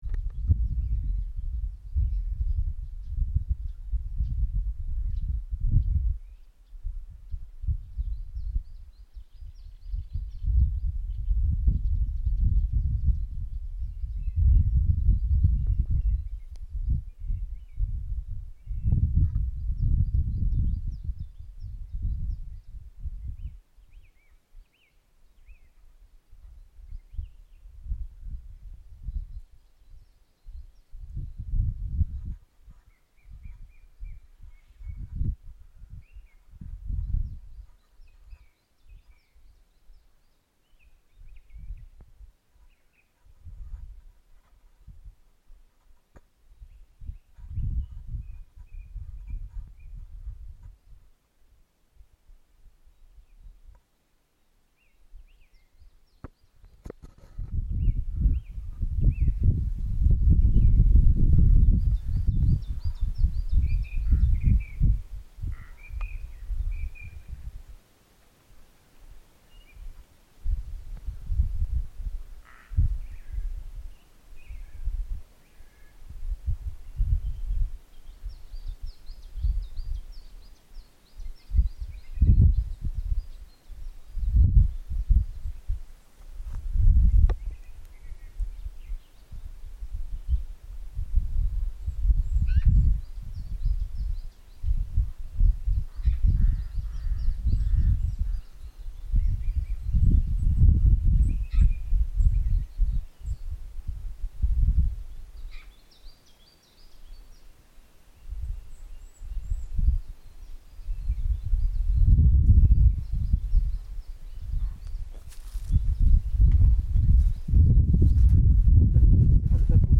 On the recording, you can hear birds, rustling, and a few other people, perhaps you can hear the wind.
Recorded in Kemerovo, Russia